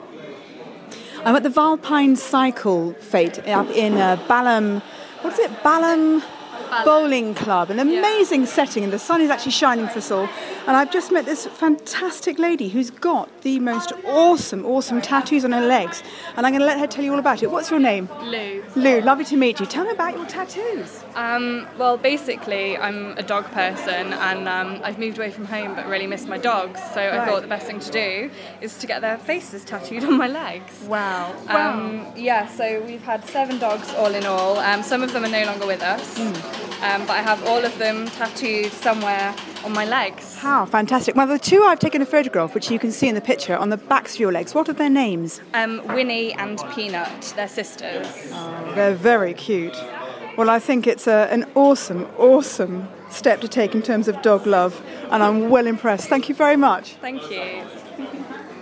Dedicated follower... At the Vulpine Fête
We met some brillliantly enthusiastic bicyles designers, builders, equippers and die-hard cyclists. This lady was one such enthusiast... who also felt equally dedicated to her canine friends.